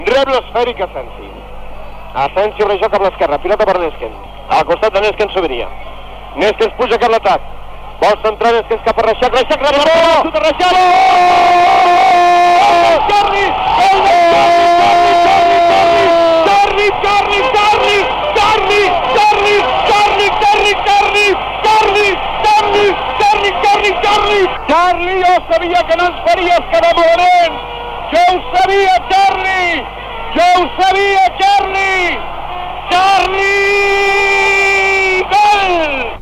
Transmissió de la final de la Recopa d' Europa de futbol entre el FC Barcelona i el Fortuna de Düsseldorf al Saint Jakob Stadium, de Basilea (Suïssa).
Esportiu